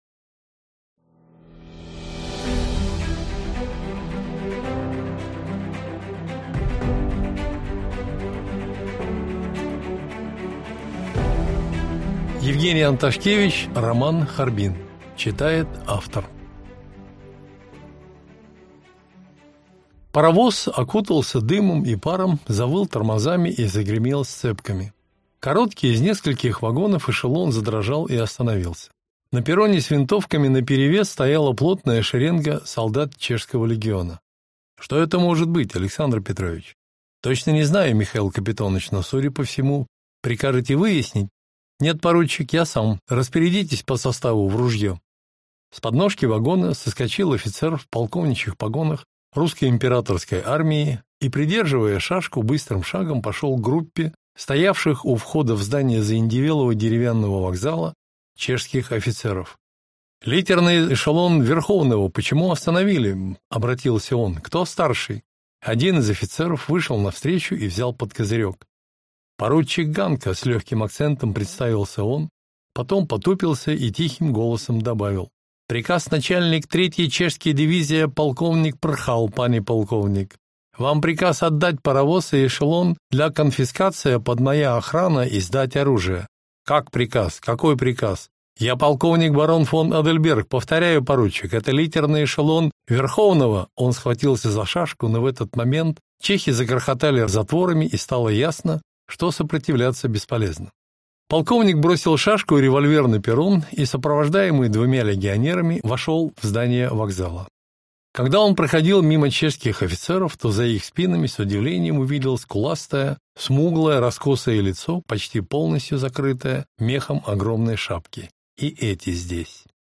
Аудиокнига Харбин | Библиотека аудиокниг